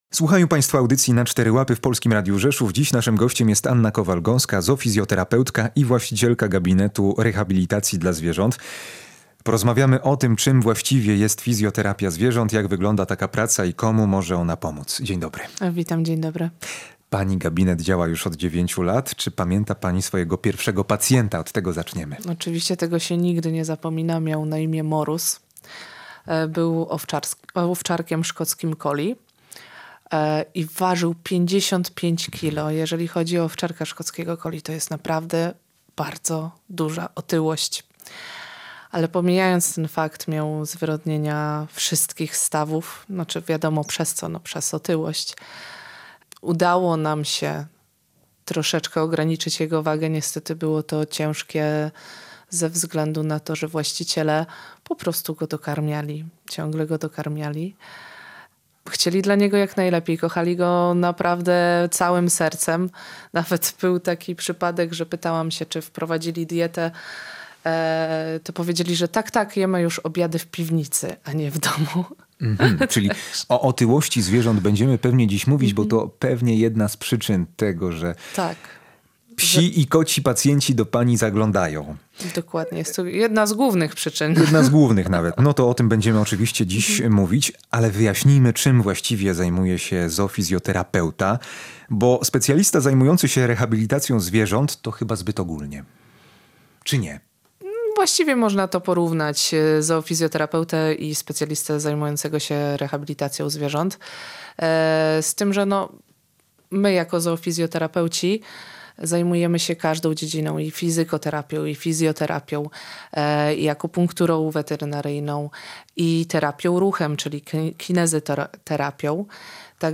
O swojej pracy opowiedziała w audycji „Na cztery łapy”.